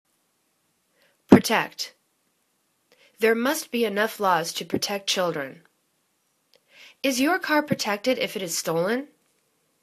pro.tect      /prə'tekt/    v